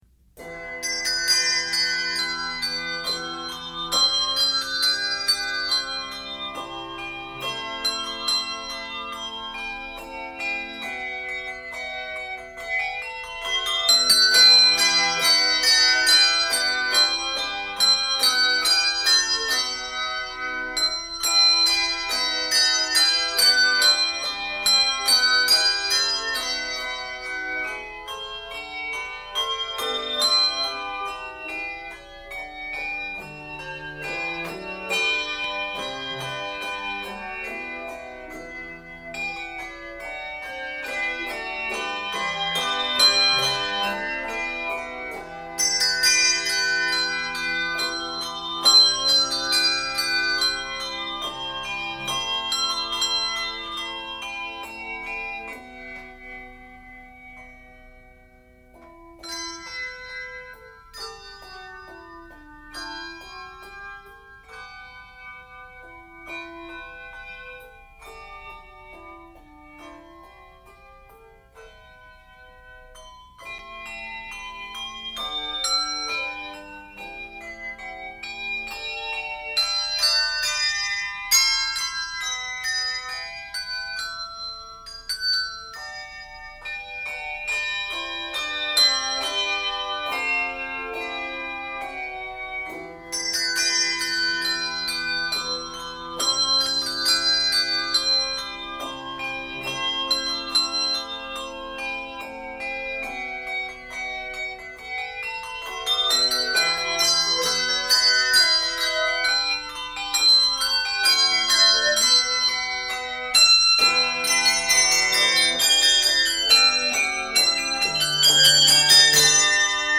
Large chords make use of the full range of bells.